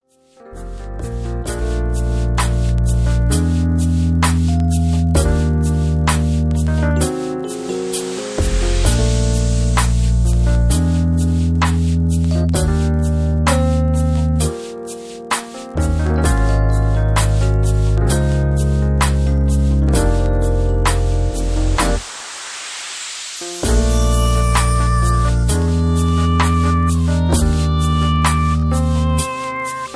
(Key-Bb) Karaoke MP3 Backing Tracks
Just Plain & Simply "GREAT MUSIC" (No Lyrics).
Tags: karaoke , mp3 backing tracks